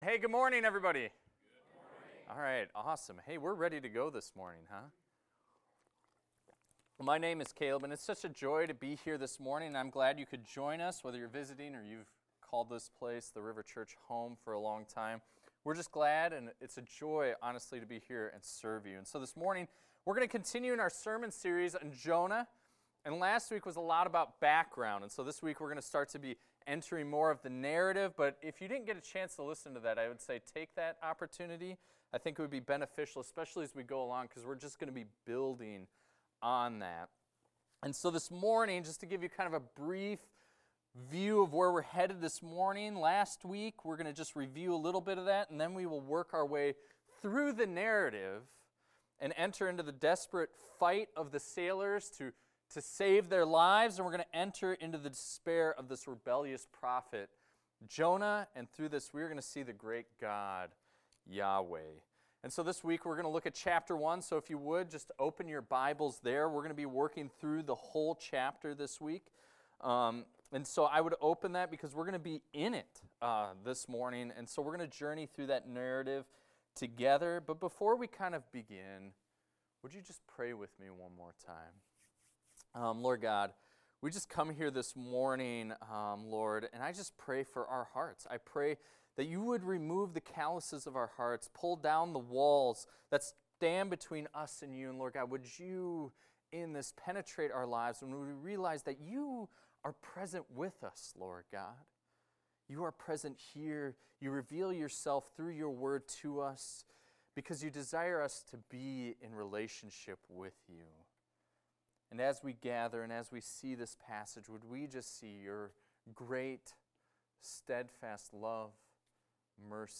This is a recording of a sermon titled, "Chapter 1."